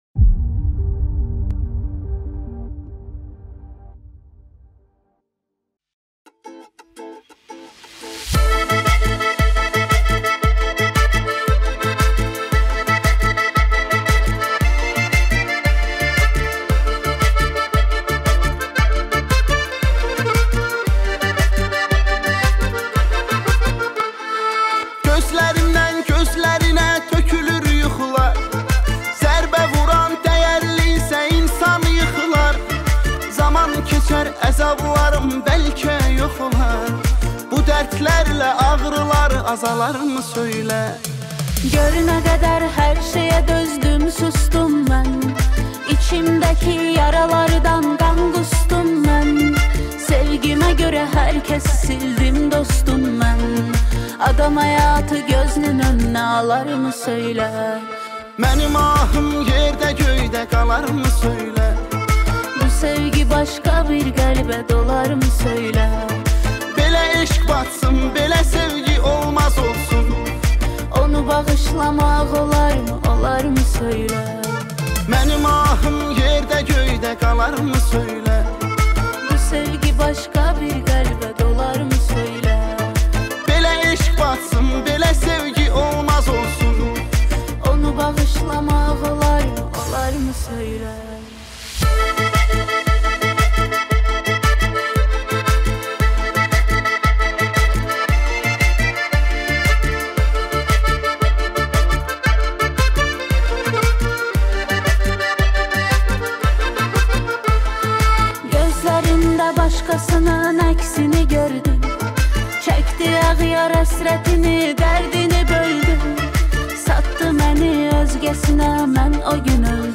دسته بندی : آهنگ ترکی تاریخ : سه‌شنبه 19 جولای 2022